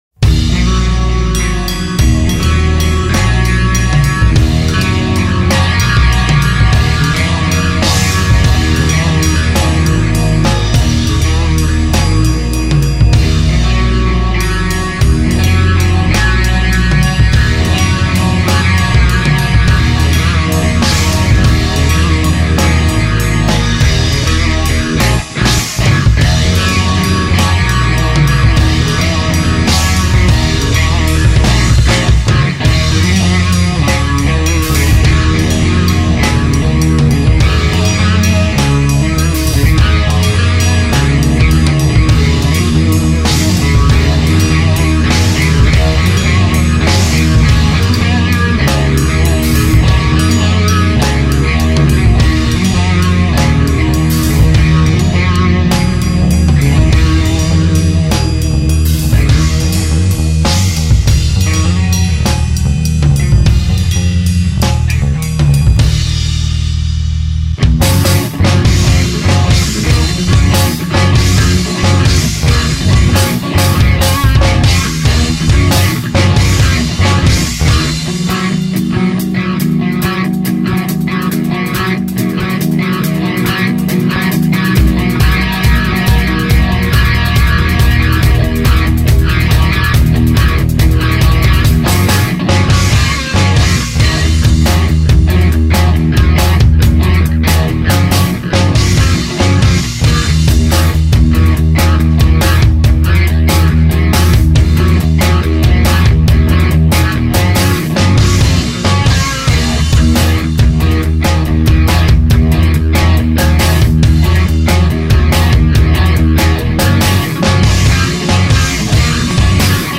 predstavujem moju kapelku, momentalne hravame v zostave 2 gitary + bicie, basaka stale hladame. Prikladam ukazku (na ktorej som nahraval basu) na ilustraciu nasej hudobnej orientacie.
mne sa paci. ani som nevedel ako a ubehla mi ta mp3, mozno miestami na moj vkus prilis monotonne,ale spev by isto spravil svoje.
Dobre sa to pocuva, len mi trochu vadi, ze produkcia je celkovo akasi malo dynamicka, mdla.